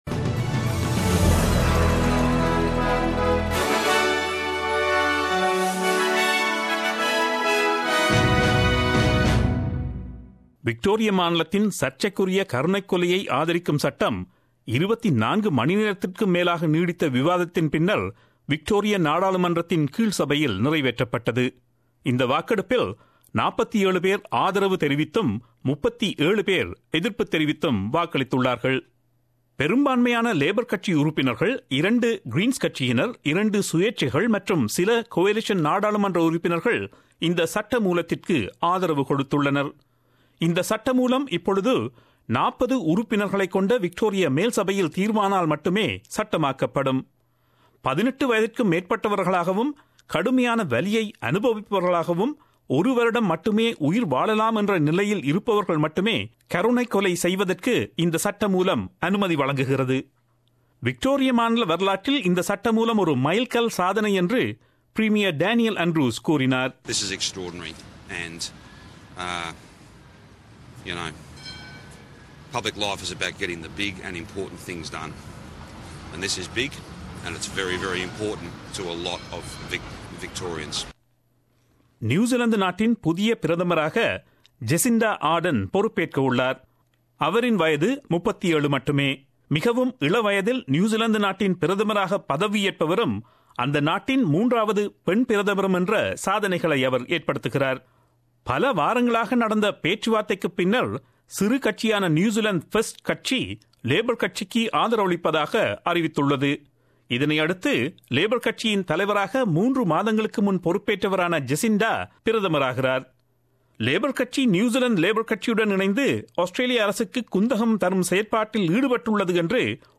Australian news bulletin aired on Friday 20 October 2017 at 8pm.